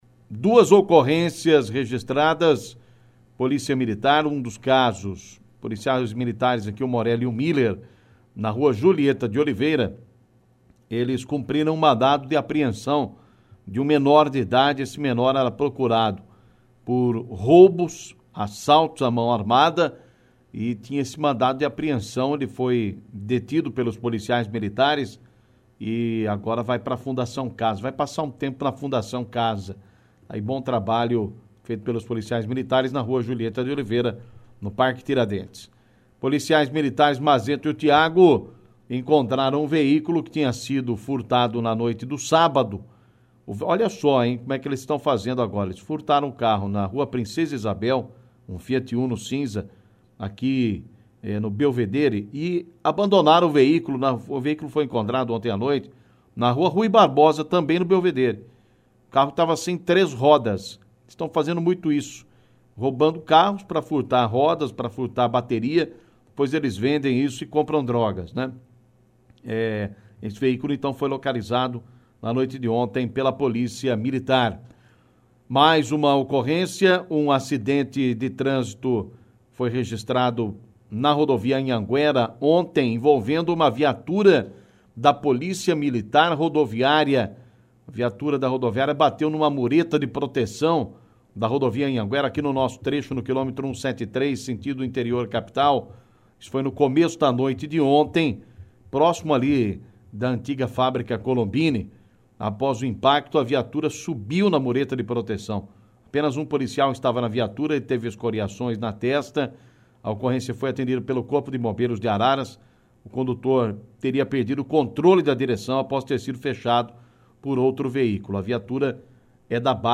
Polícia